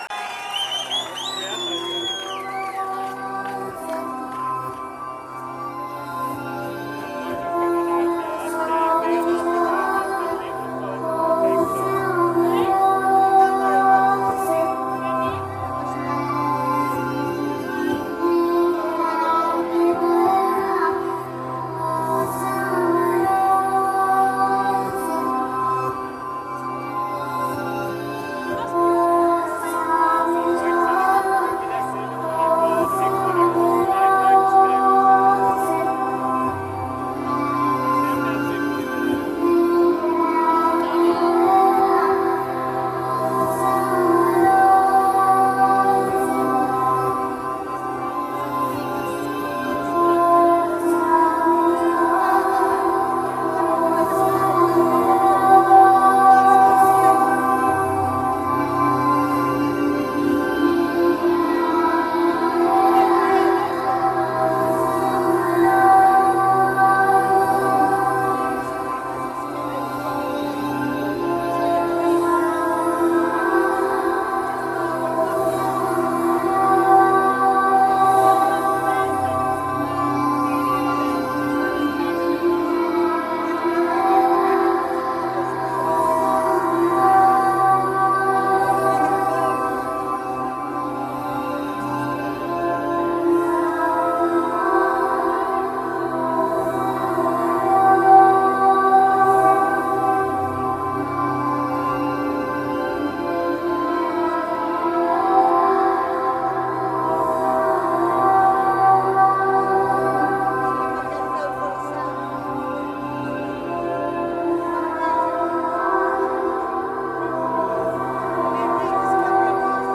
full set
venue The Old Truman Brewery